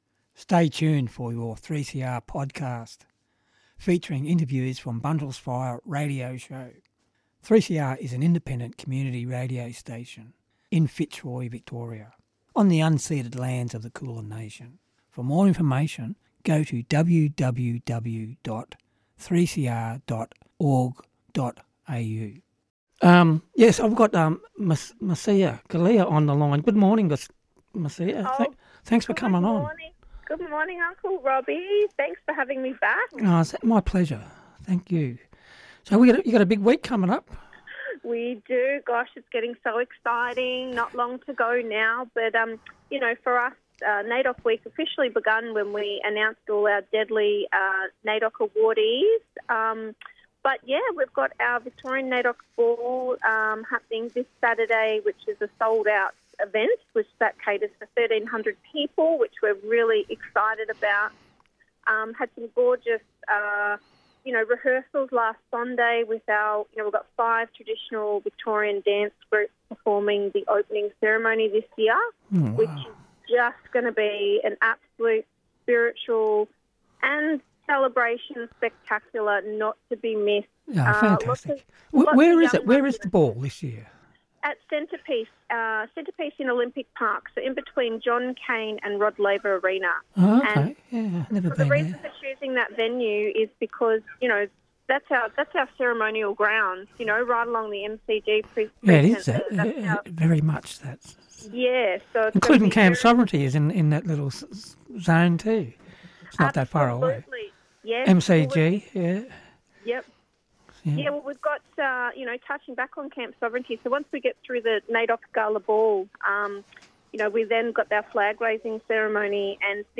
Presenter